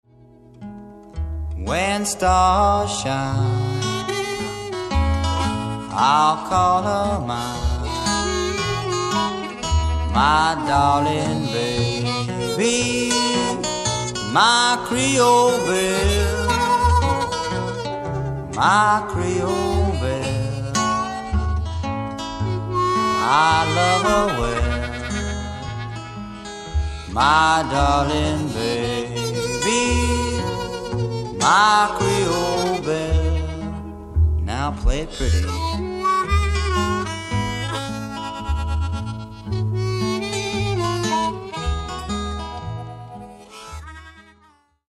60年代の「コーヒーハウス」文化を匂わす温もりのある録音が聴き所。
ハーモニカ